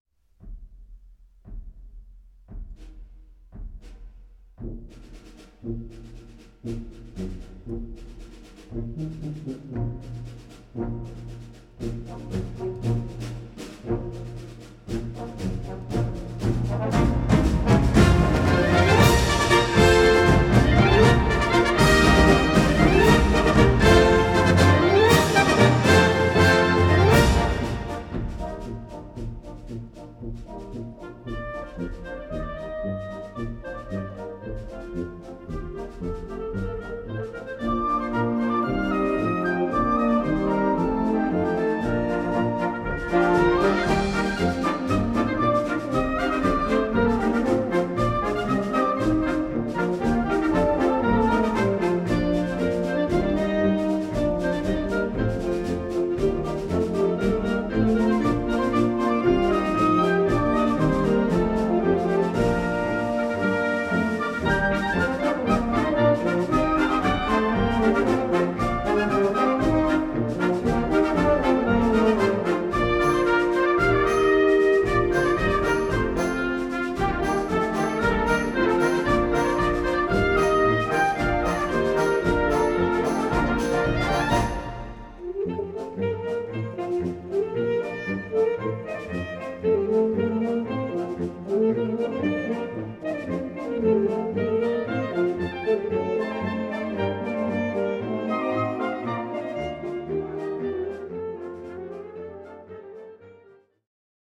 Répertoire pour Harmonie/fanfare - Marches de concert